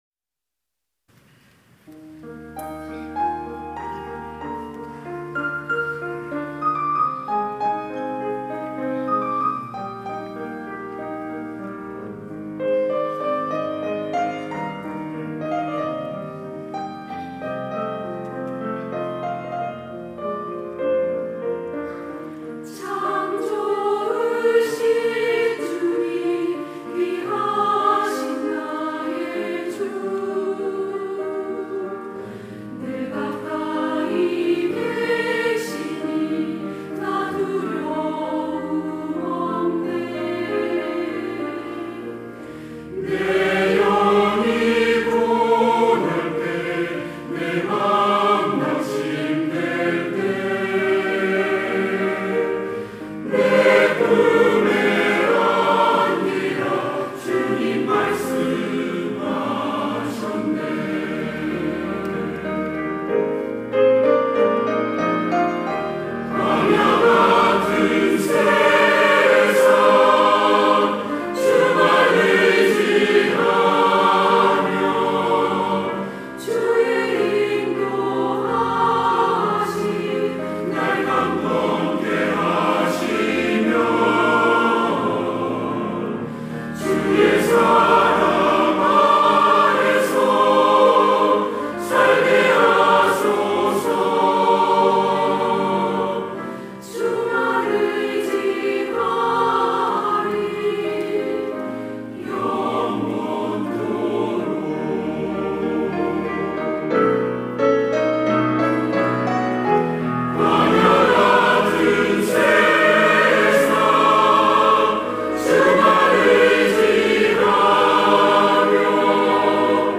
시온(주일1부) - 참 좋으신 주님
찬양대 시온